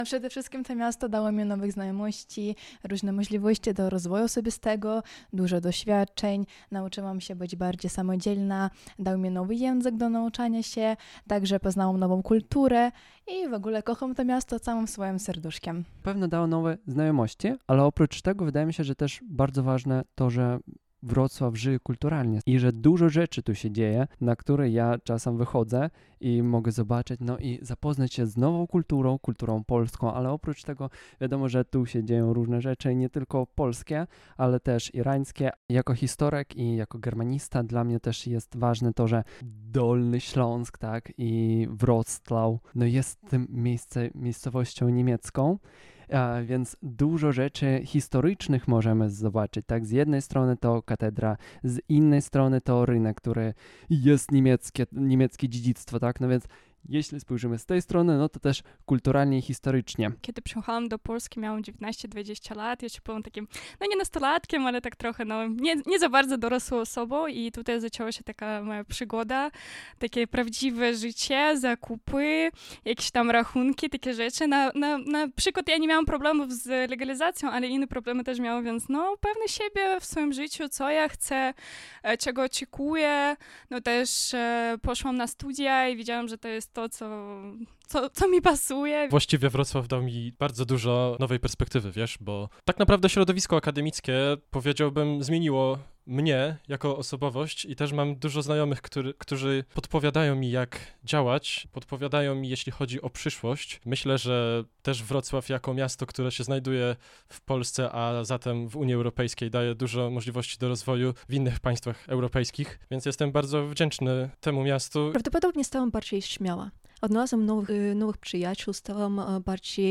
sonda_wroclaw-1.mp3